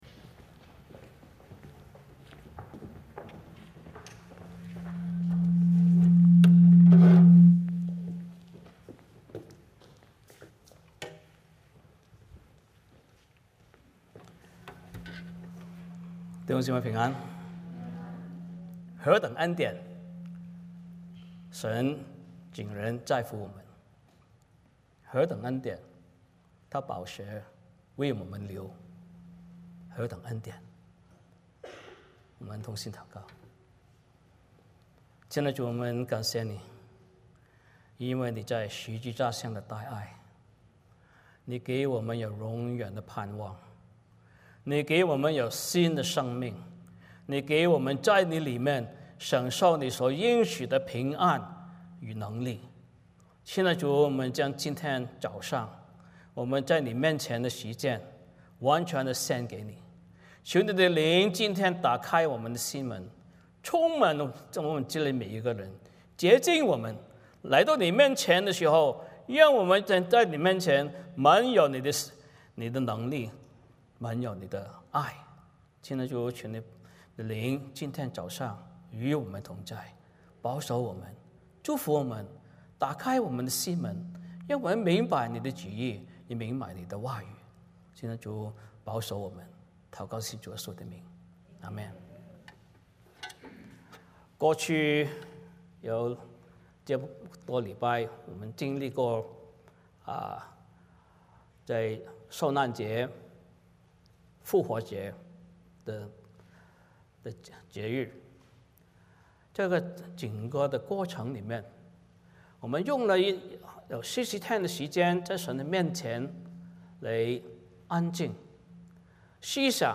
使徒行传 1:1-11 Service Type: 主日崇拜 欢迎大家加入我们的敬拜。